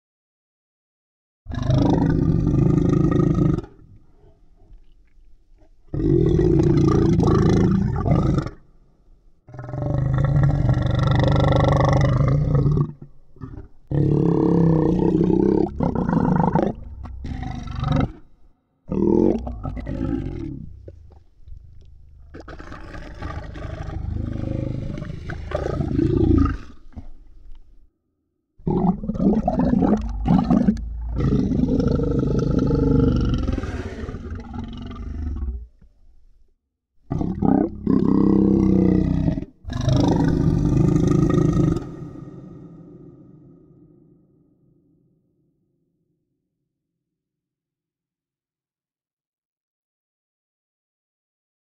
دانلود آهنگ اژدها 4 از افکت صوتی انسان و موجودات زنده
جلوه های صوتی
دانلود صدای اژدها 4 از ساعد نیوز با لینک مستقیم و کیفیت بالا